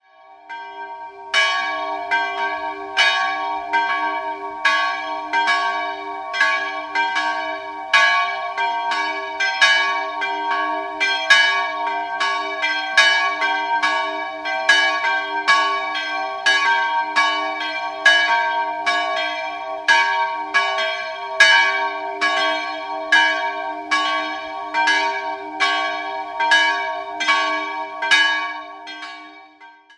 Die hübsche Marienkirche, eine Filiale von Kevenhüll, wurde wohl im Jahr 1840 erbaut 2-stimmiges Geläute: cis''-g'' Nähere Daten liegen nicht vor.